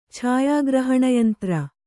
chāyā grahaṇa yantra